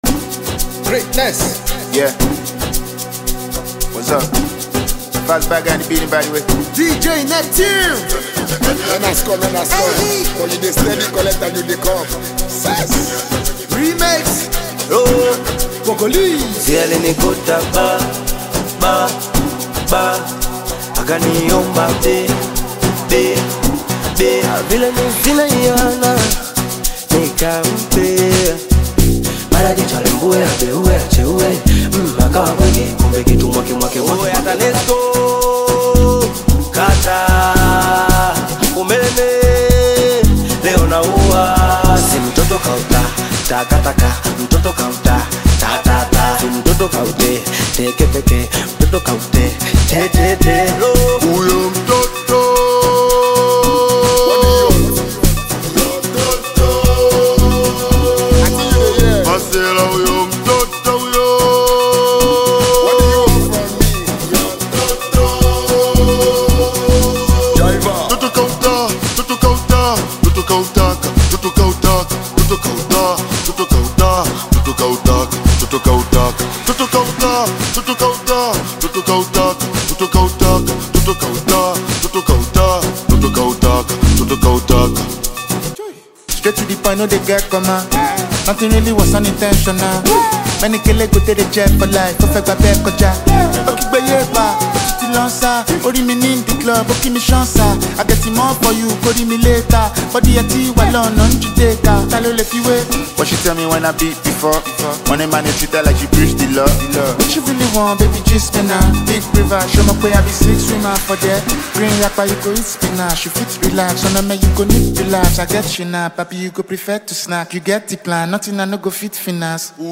Bongo Flava music track
Bongo Flava You may also like